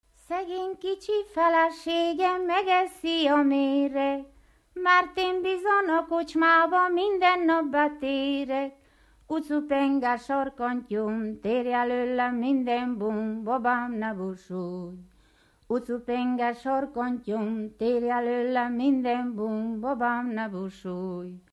Moldva és Bukovina - Bukovina - Hadikfalva
ének
Duda-kanász mulattató stílus